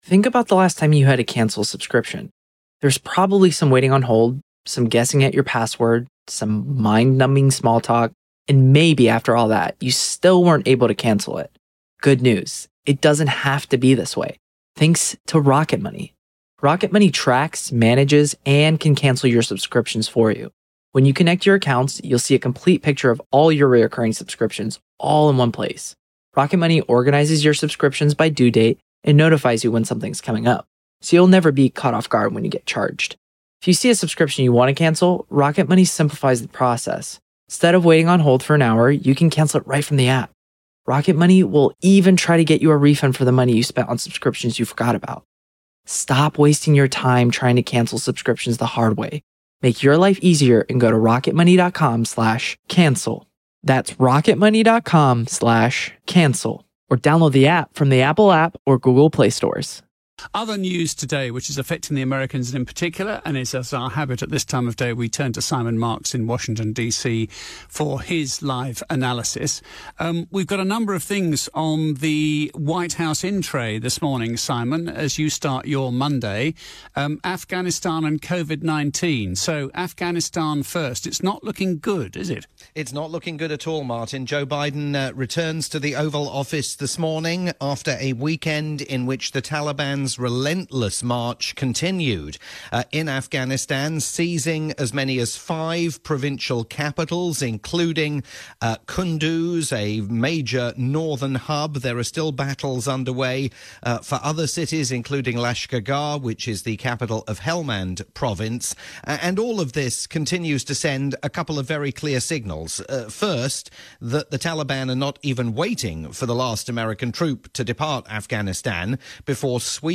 live roundup for LBC News